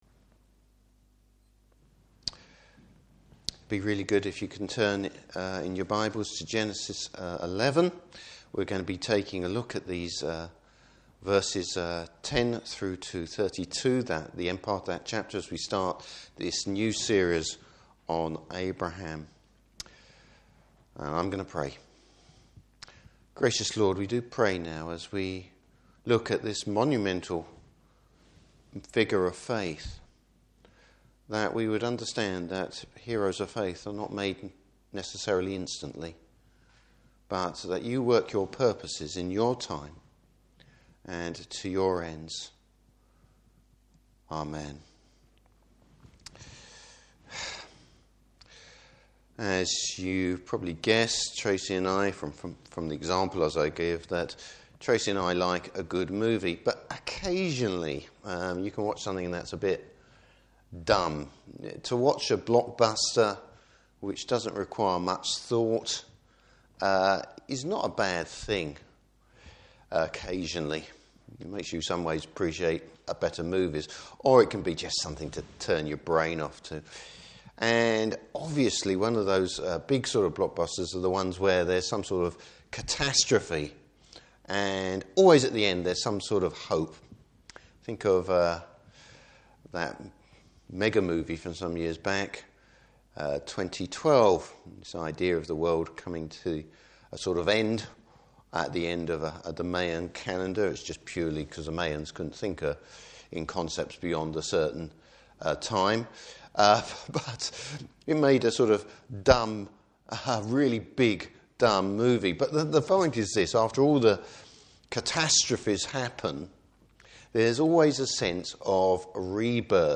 Passage: Genesis 11:10-32. Service Type: Evening Service Bible Text: Genesis 11:10-32.